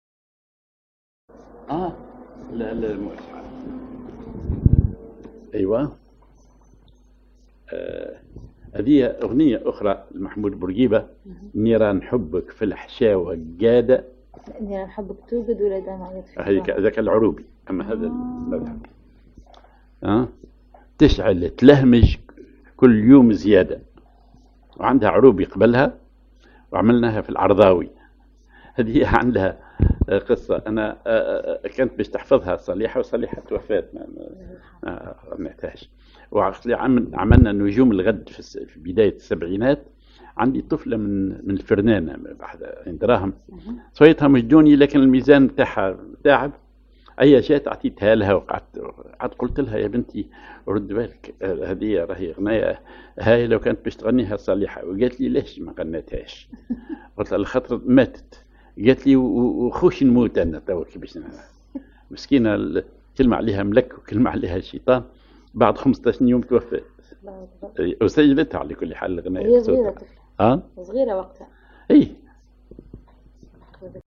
ar عرضاوي
ar الوحدة
أغنية